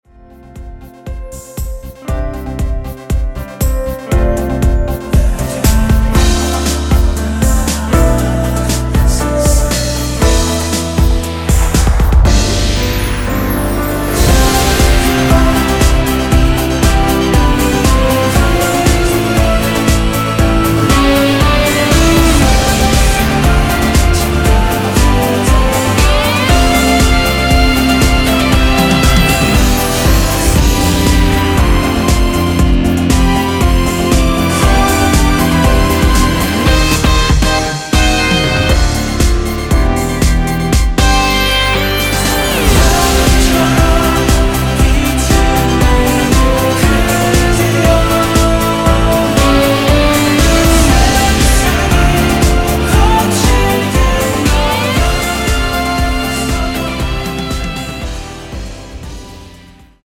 원키에서(-3)내린 코러스 포함된 MR입니다.(미리듣기 확인)
◈ 곡명 옆 (-1)은 반음 내림, (+1)은 반음 올림 입니다.
앞부분30초, 뒷부분30초씩 편집해서 올려 드리고 있습니다.